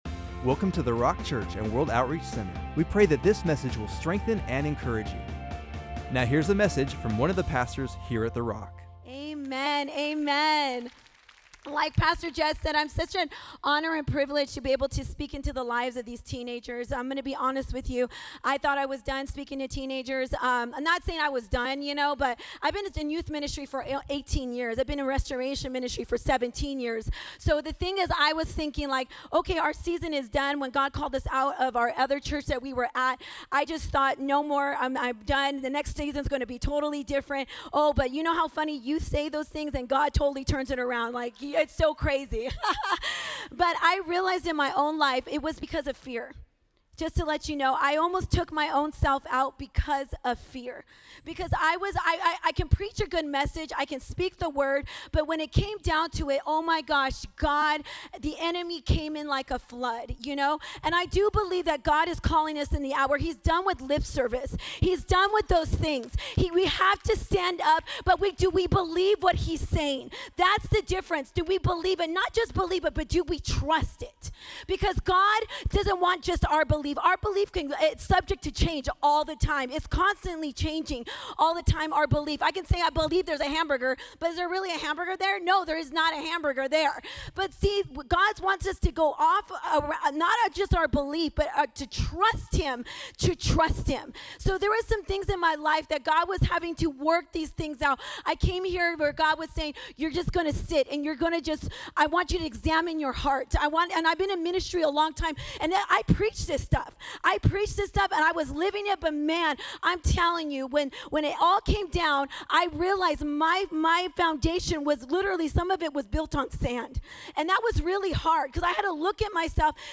Saints Gathering 2021